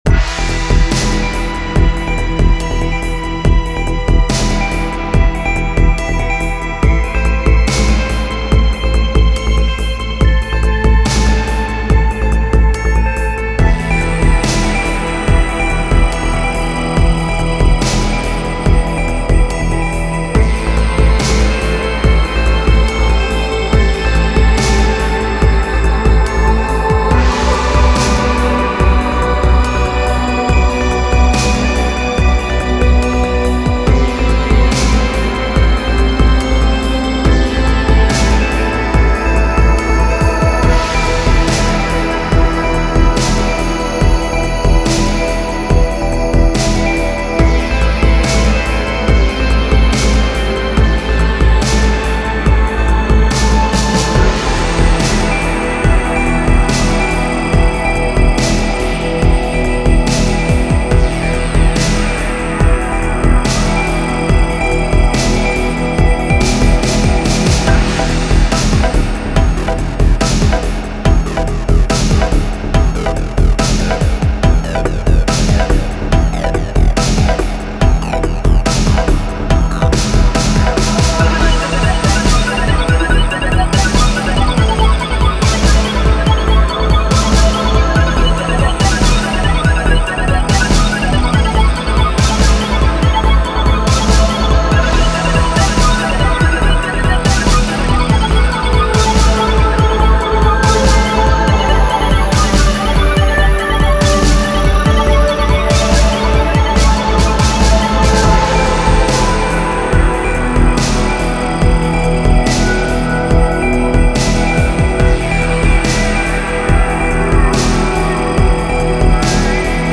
Home > Music > Electronic > Medium > Laid Back > Chasing